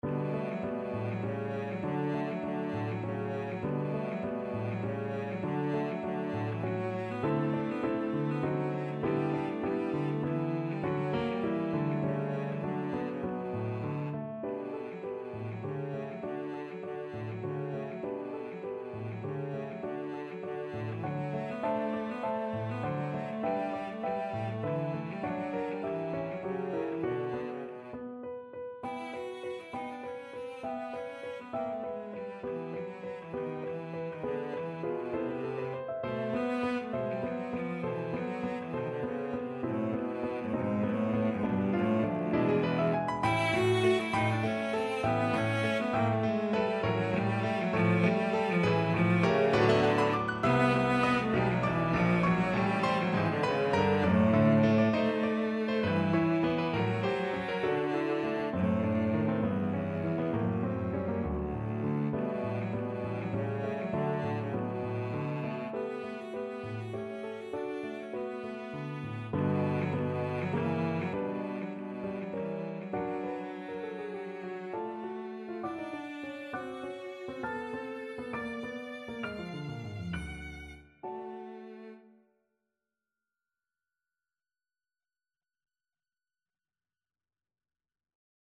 Classical Ravel, Maurice A La Maniere de Borodine (Valse) Cello version
Cello
D major (Sounding Pitch) (View more D major Music for Cello )
3/4 (View more 3/4 Music)
Allegro giusto =200 (View more music marked Allegro)
Classical (View more Classical Cello Music)